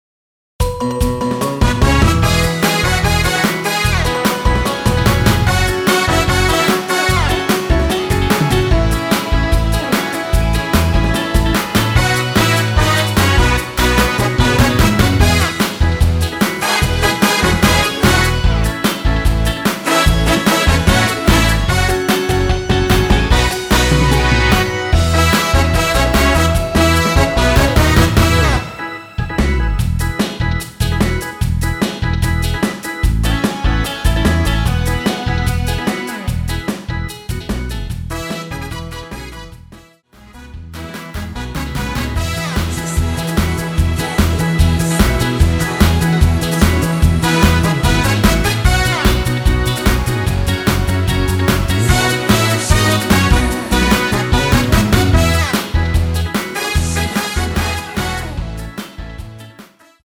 원키 코러스 포함된 MR 입니다.(미리듣기 참조)
앞부분30초, 뒷부분30초씩 편집해서 올려 드리고 있습니다.